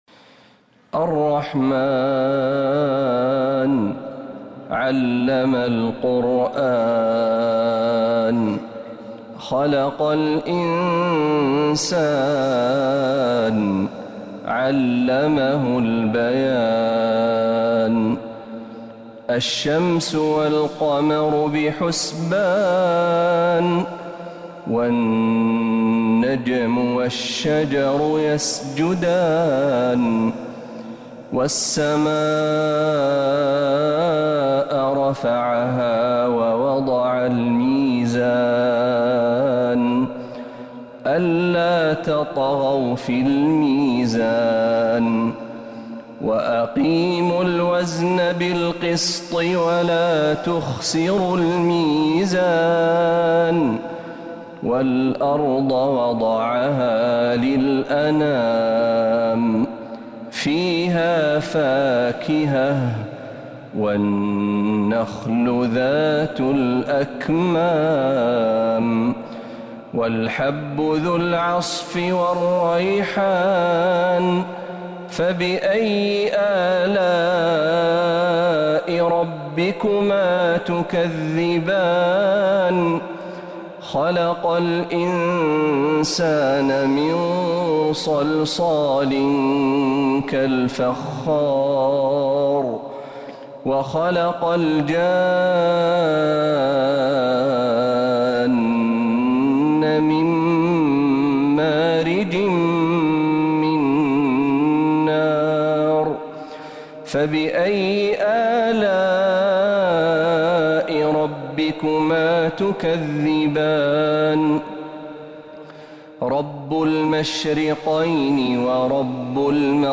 سورة الرحمن | رجب 1447هـ > السور المكتملة للشيخ محمد برهجي من الحرم النبوي 🕌 > السور المكتملة 🕌 > المزيد - تلاوات الحرمين